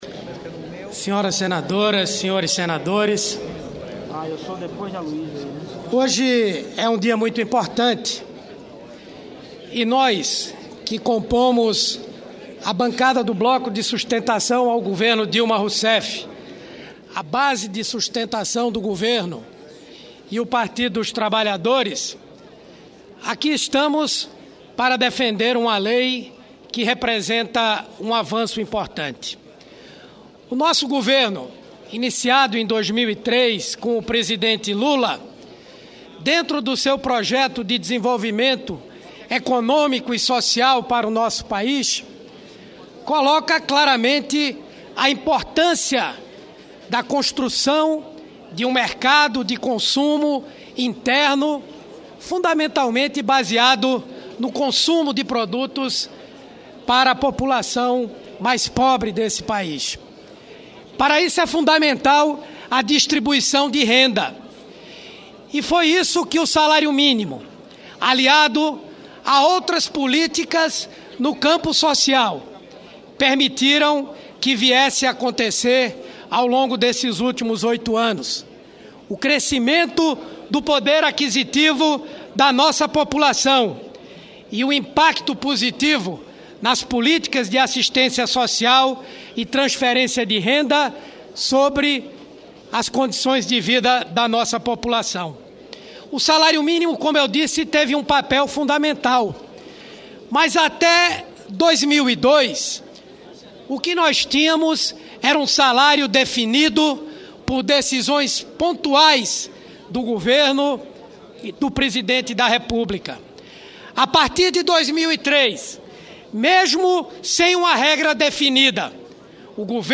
Discurso do senador Humberto Costa
Plenário